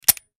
wpn_pistol10mm_firedry.wav